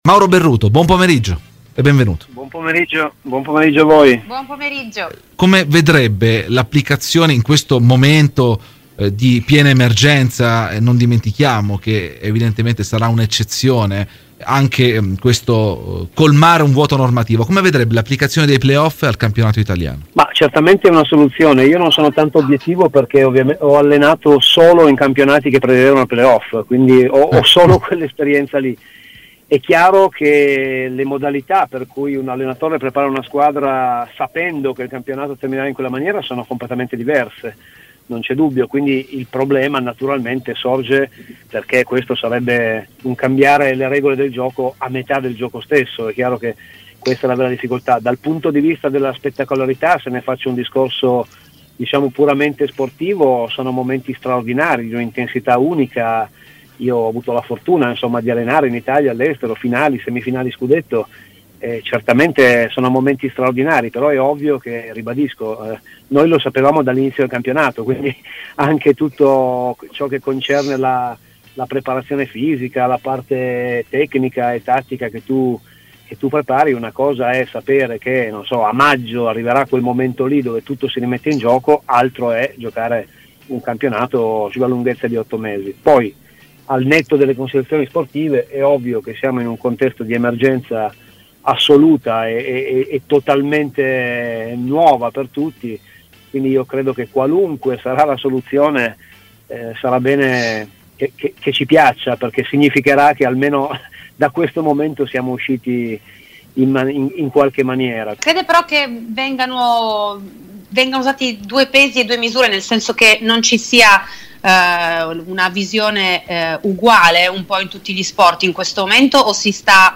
Ai microfoni di Radio Bianconera, nel corso di ‘Terzo Tempo’, è intervenuto l’allenatore di pallavolo Mauro Berruto: “I playoff per il campionato italiano?